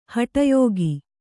♪ haṭa yōgi